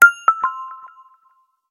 alert.wav